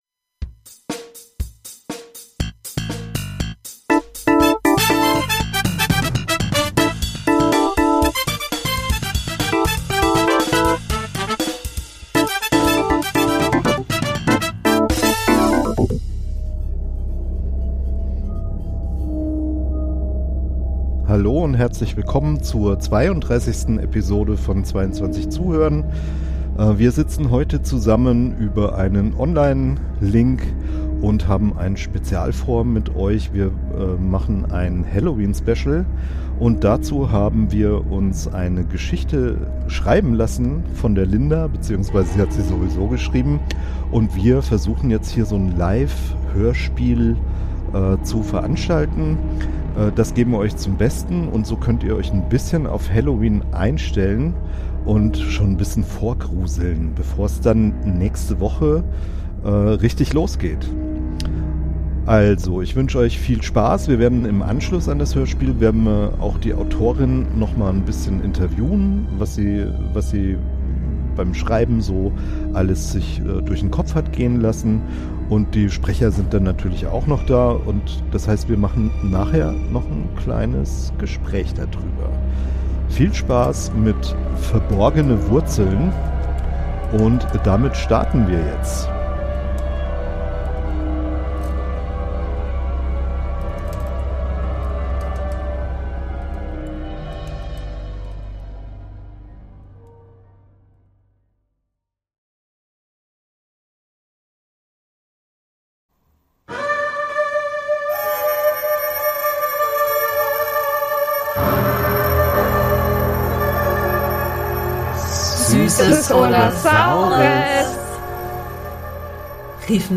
Eine Live-Hörspiel-Aufnahme